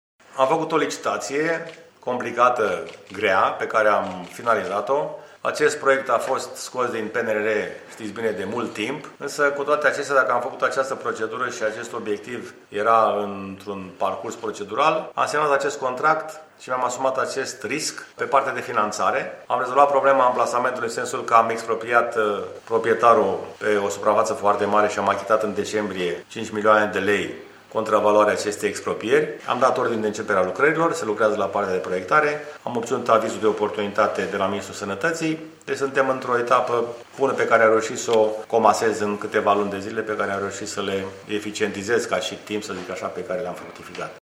Primarul George Scripcaru: